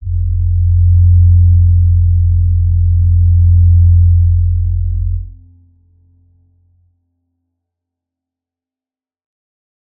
G_Crystal-F2-f.wav